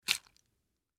На этой странице собраны натуральные звуки приготовления яичницы: от разбивания скорлупы до аппетитного шипения на сковороде.
Яичницу переложили в тарелку прямо со сковородки